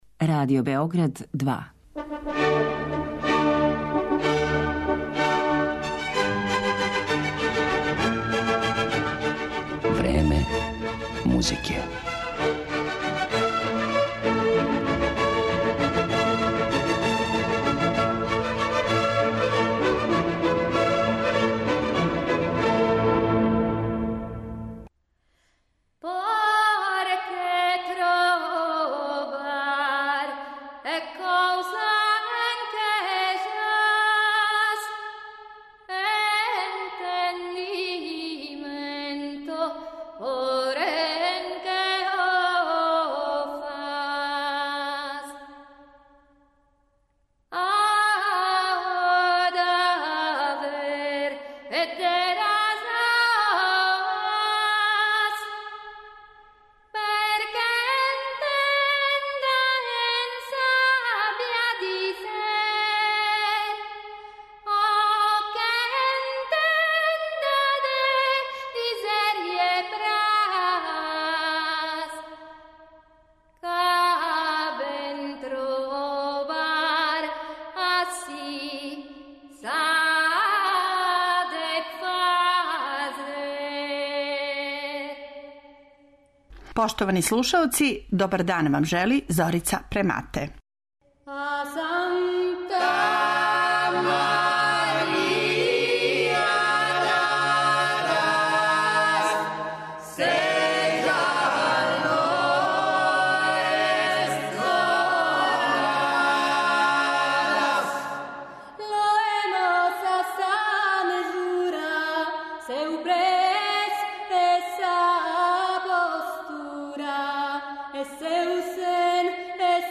Одабране песме из ове збирке извешће ансамбл за стару музику Theatrum instrumentorum